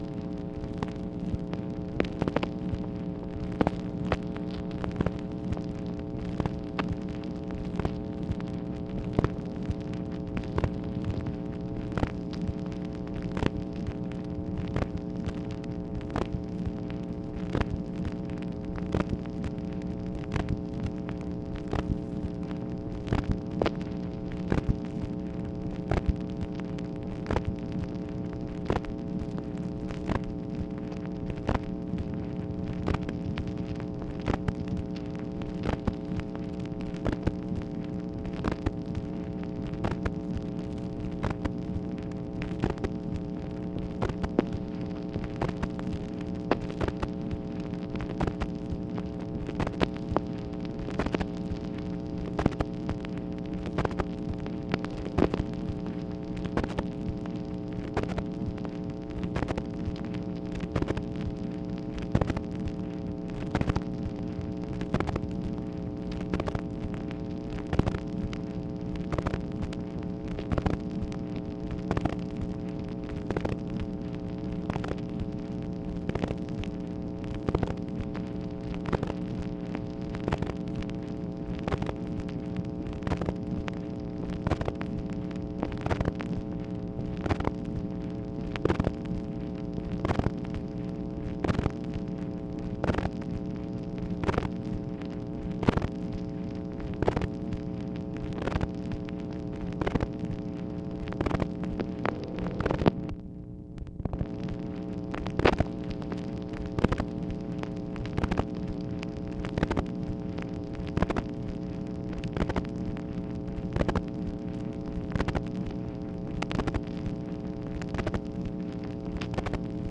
MACHINE NOISE, August 5, 1964
Secret White House Tapes | Lyndon B. Johnson Presidency